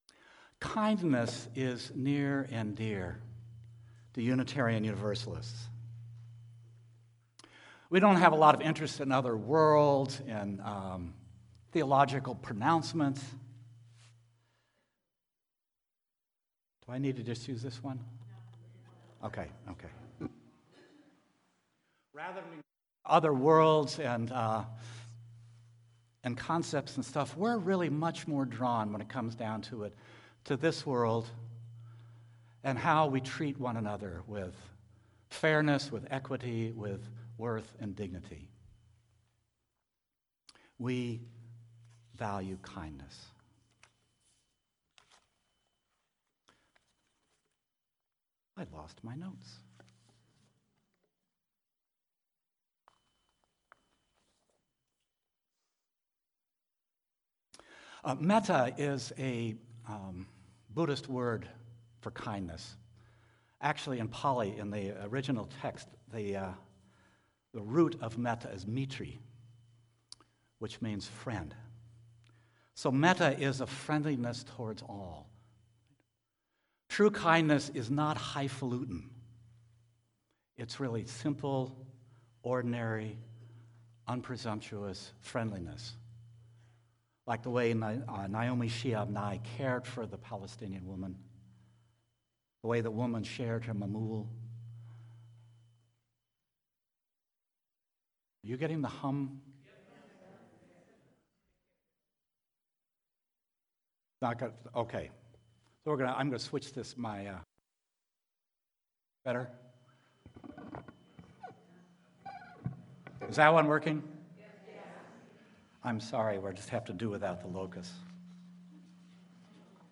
Sermon-Kindness-and-the-Patient-Heart.mp3